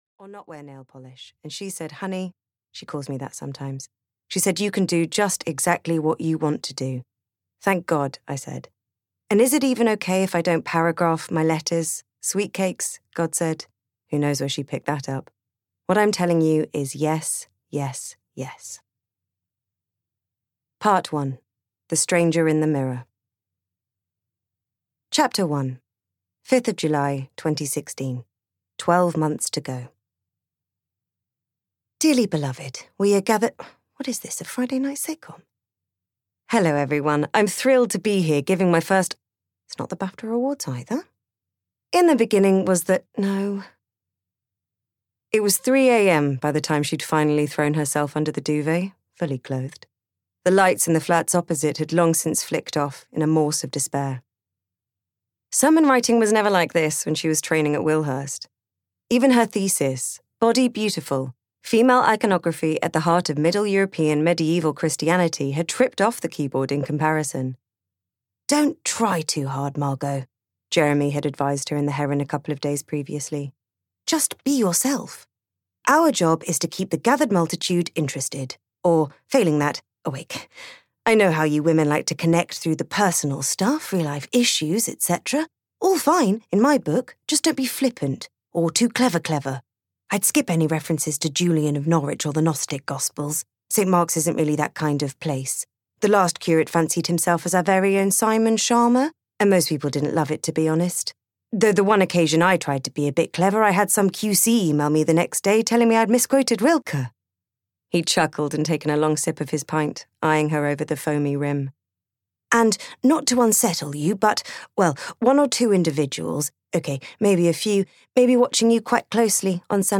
The Girls' Book of Priesthood (EN) audiokniha
Ukázka z knihy